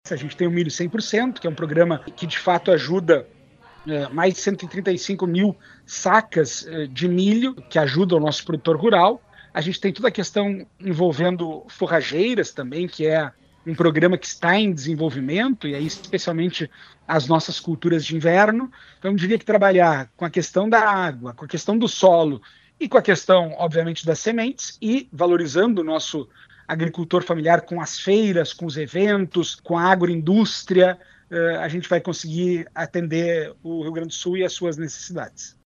No Progresso Rural, ontem pela manhã, Gustavo Paim ainda abordou a importância do programa de repasse de sementes a produtores. (Abaixo, áudio de Gustavo Paim)